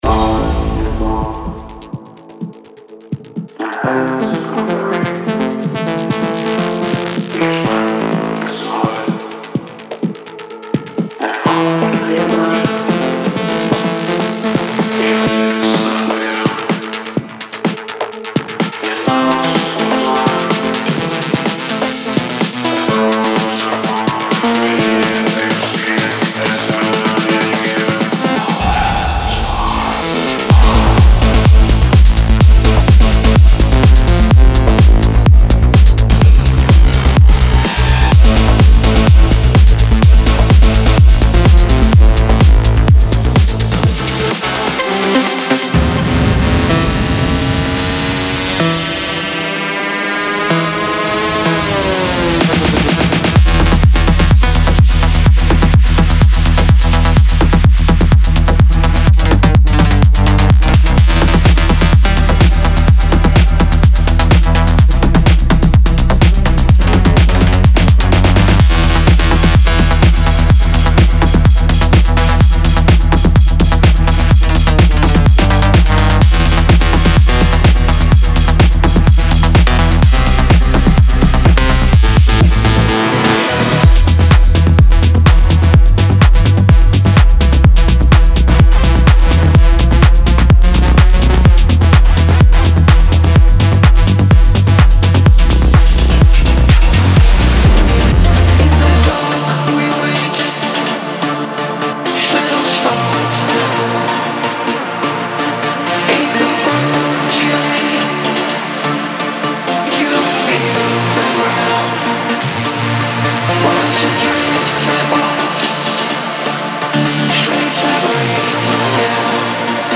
デモサウンドはコチラ↓
Genre:Melodic Techno
126 BPM
390 Wav Loops (Basses, Synths, Drums, Vocals, Fx & more)